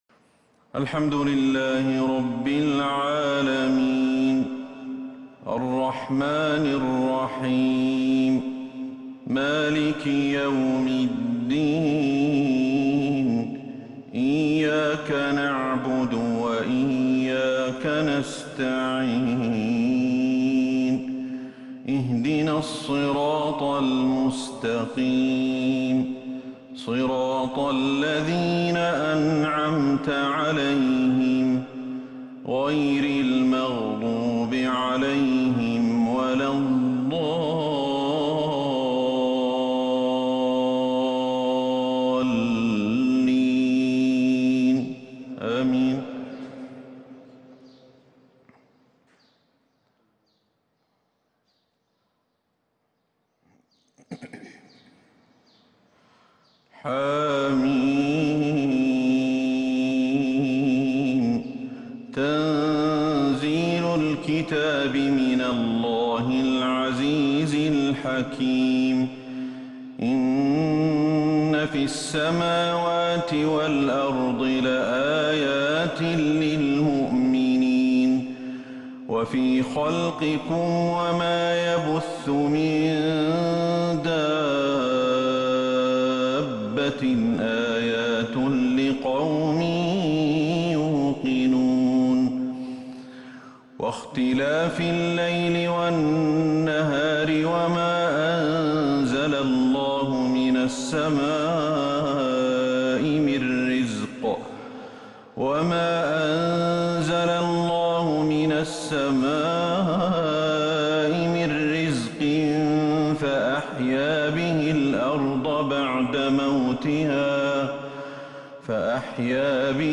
فجر 5 شعبان 1442 هـ فواتح سورة الجاثية > 1442 هـ > الفروض - تلاوات الشيخ أحمد الحذيفي